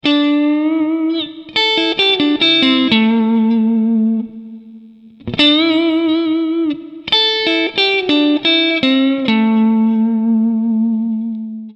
Fraseggio blues 01
Nel primo esempio, suonato sul box 2 in quinta posizione, partiamo con il bending della nota D che sale di un tono fino alla nota E. Il valore tonale di questa nota è relativa alla precisione con il quale viene effettivamente tirata la corda.
L'ultima nota, cioè il A sulla quarta corda, viene fatta vibrare.